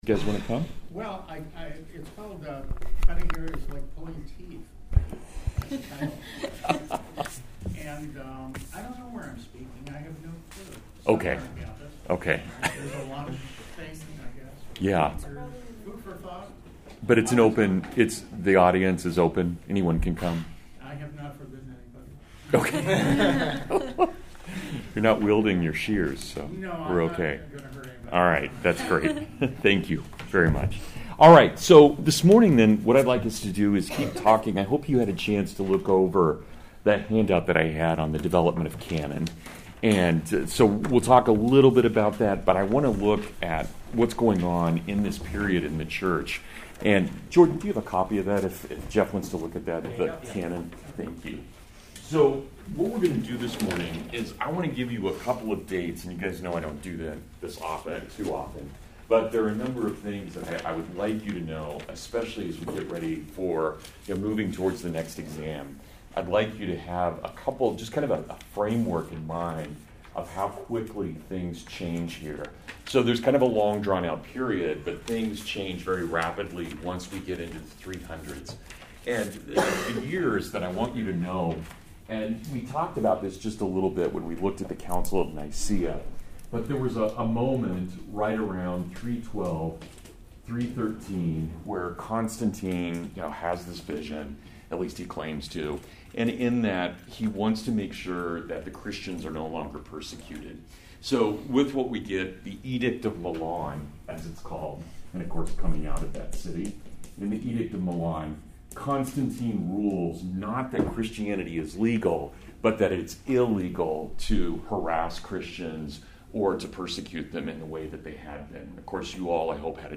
Martyrdom and the Early Church (Full Lecture)